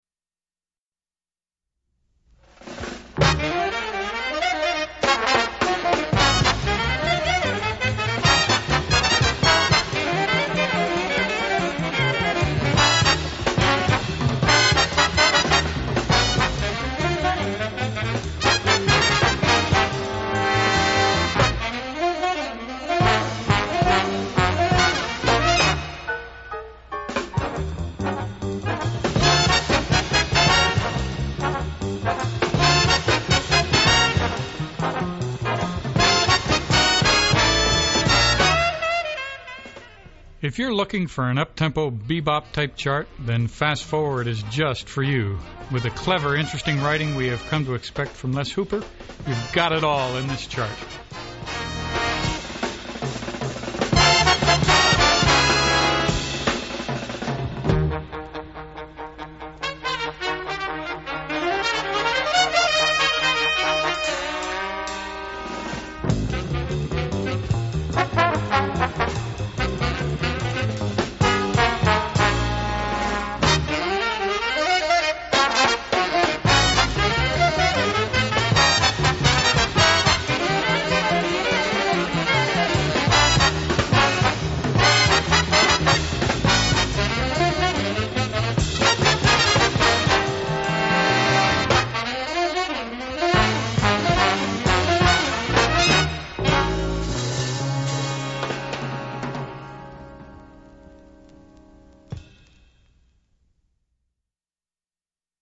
An up-tempo be-bop type chart